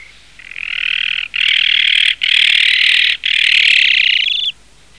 Iberian Magpie
It is a wary bird that responds loudly to any hint of danger.
Iberian-Magpie.mp3